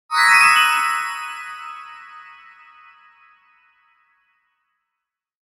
Sparkling Magic Twinkle Sound Effect
A bright and sparkling sound featuring shimmering tones that evoke wonder and enchantment. Perfect for magical transitions, fairy scenes, or any project that needs a touch of sparkle and fantasy.
Genres: Sound Logo
Sparkling-magic-twinkle-sound-effect.mp3